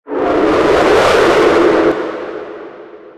tsm_flareball_attack.ogg